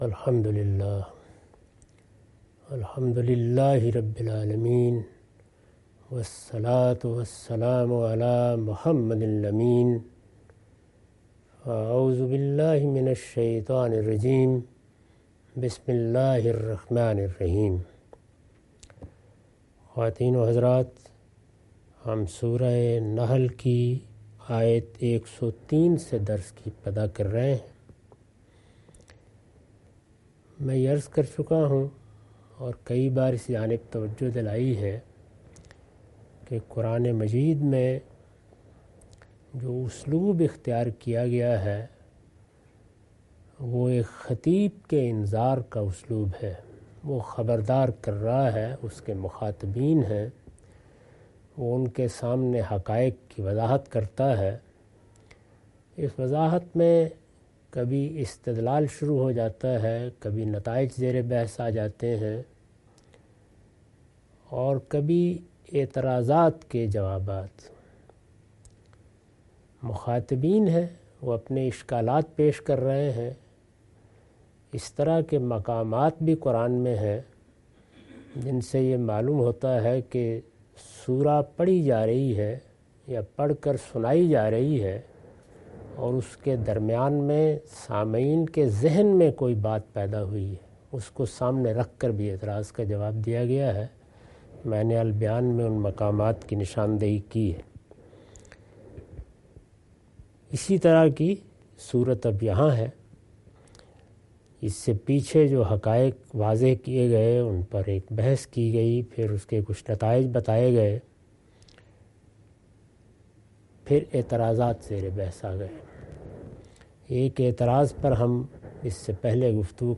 Surah Al-Nahl- A lecture of Tafseer-ul-Quran – Al-Bayan by Javed Ahmad Ghamidi. Commentary and explanation of verses 103-104.